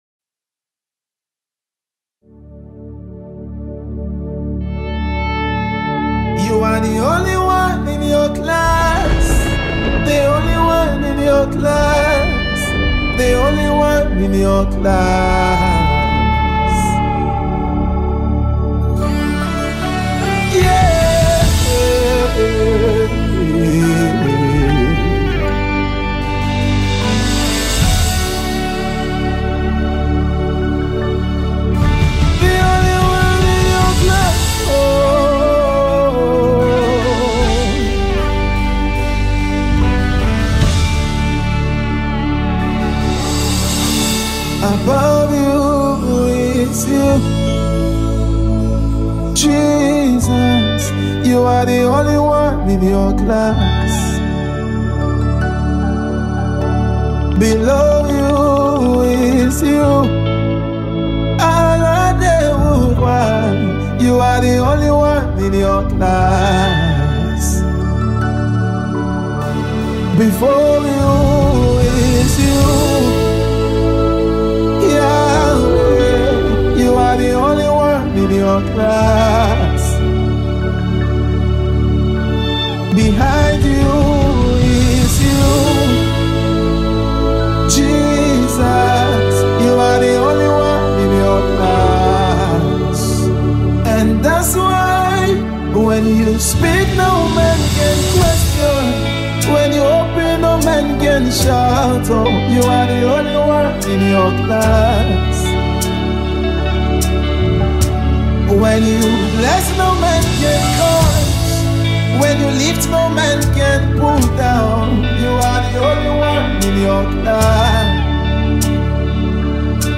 The Abuja-Based gospel music minister and recording artist